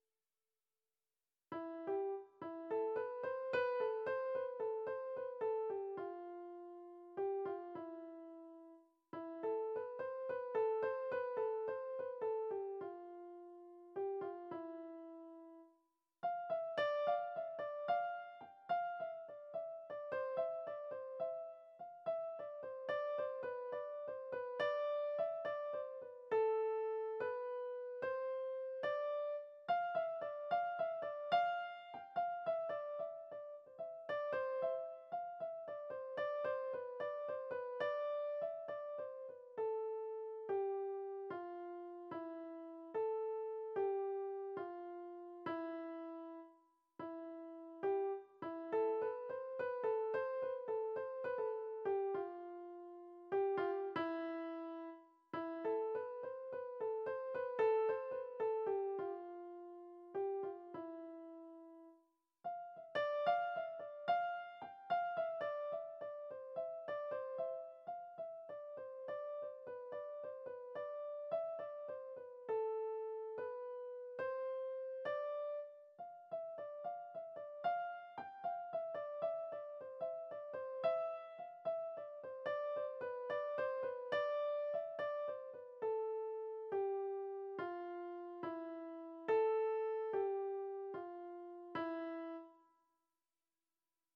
Слышал только в исполнении школьного ансамбля в 70-е годы.